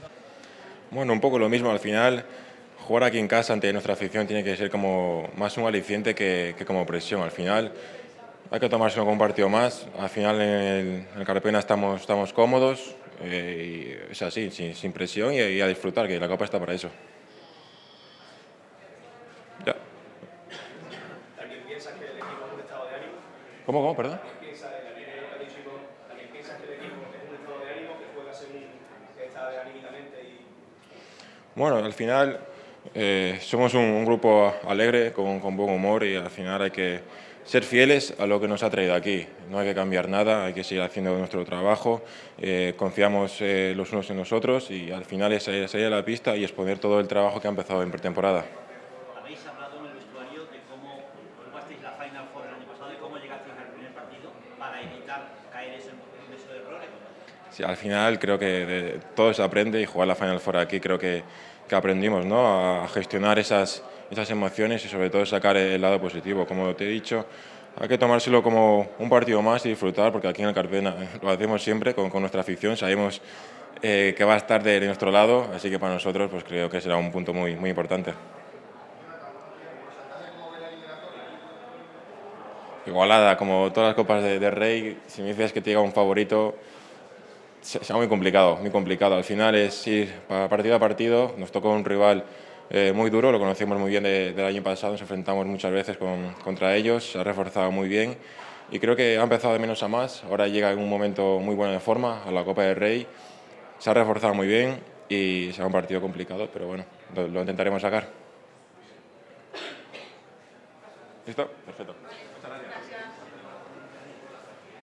Jonathan Barreiro ha comparecido ante los medios en la previa del partido ante Lenovo Tenerife.